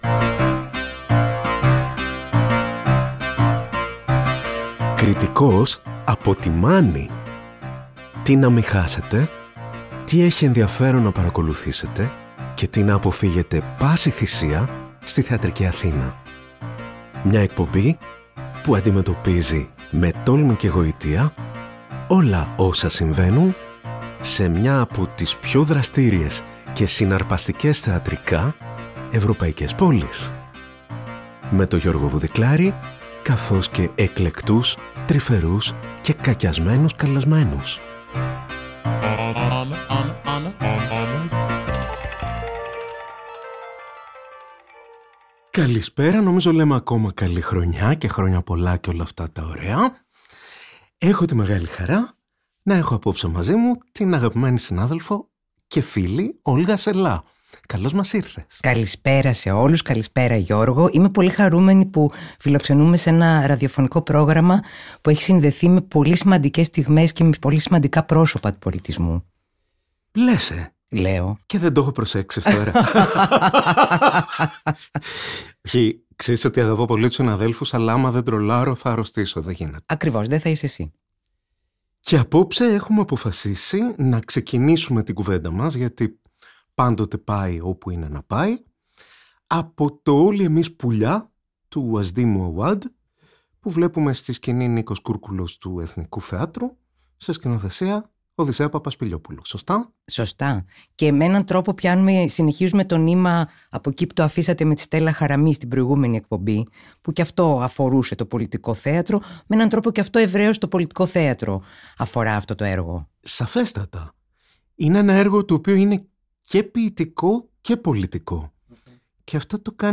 Μια νέα εκπομπή που θα μάς συστήνει όλες τις θεατρικές παραστάσεις που αξίζει να γνωρίζουμε για τους σωστούς ή τους… λάθος λόγους! Συζητήσεις μεταξύ κριτικών που συμφωνούν ή διαφωνούν για το τι δεν πρέπει να χάσουμε, αλλά και το τι πρέπει να αποφύγουμε στη θεατρική Αθήνα.